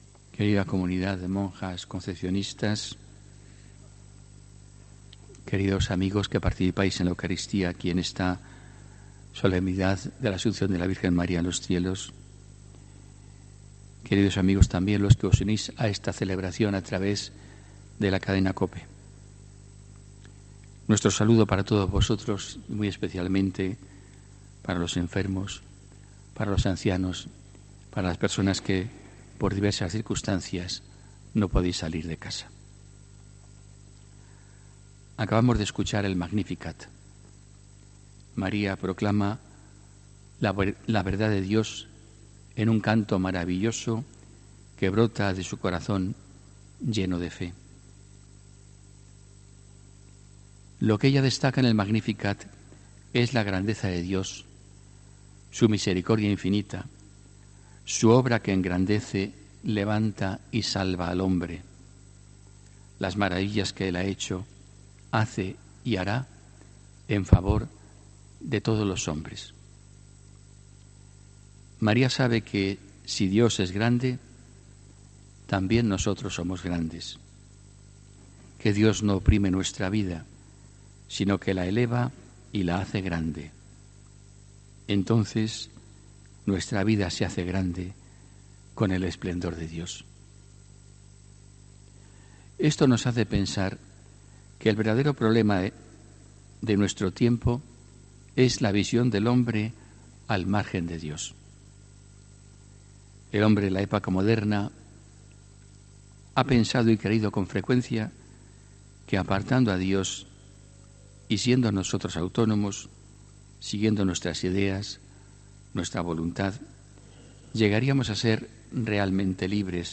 HOMILÍA 15 AGOSTO 2019